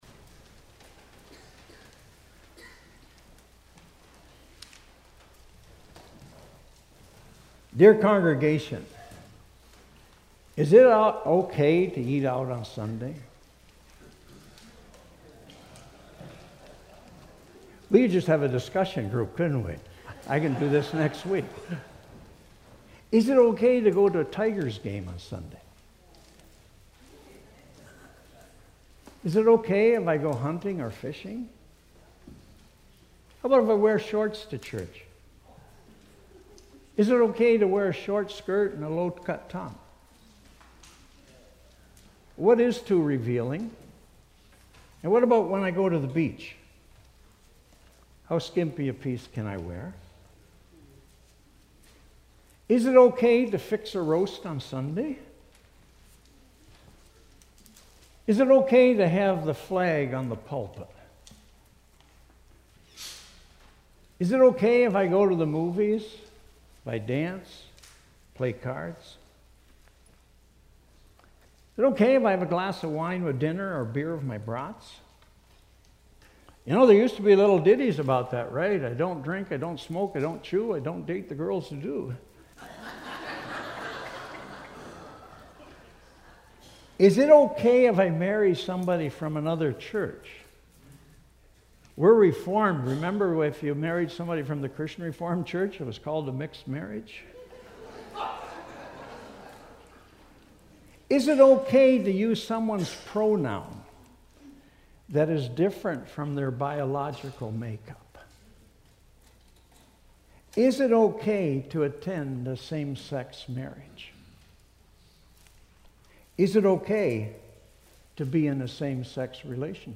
Sermons | Eighth Reformed Church